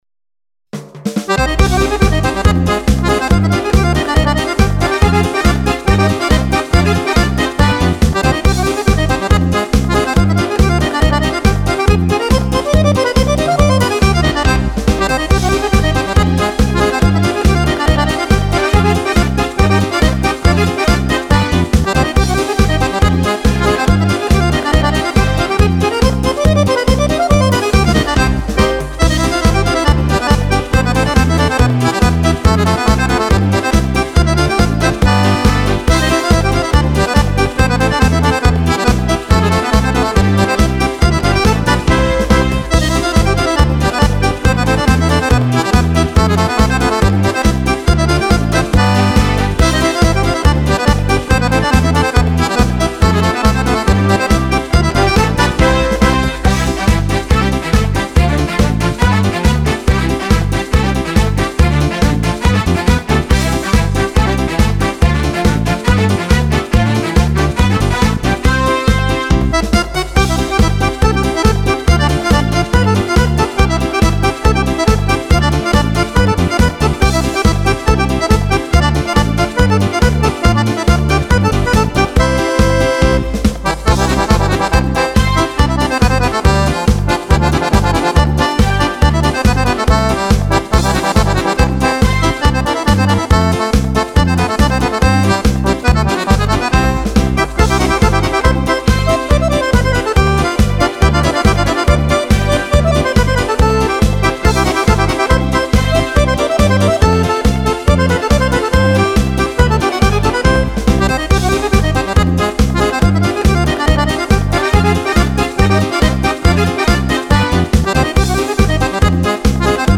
Merengue per fisarmonica